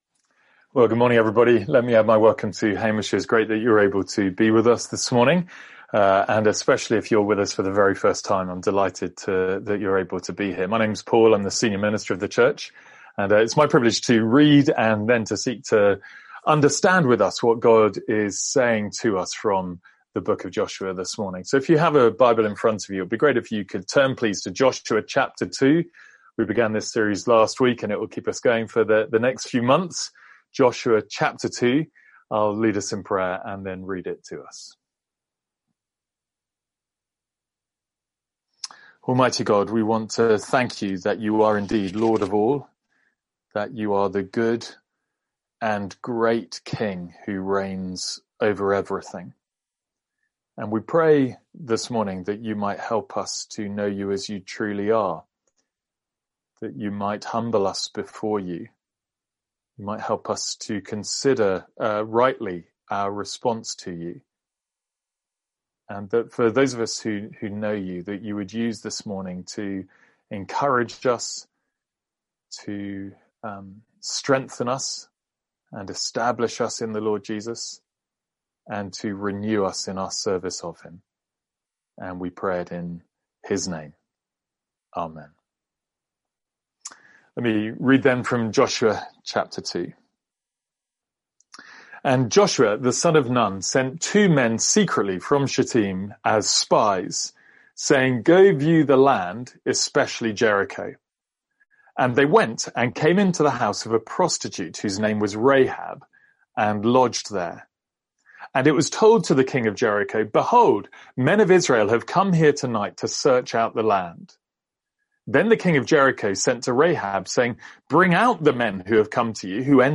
Sermons | St Andrews Free Church
From our morning series in Joshua.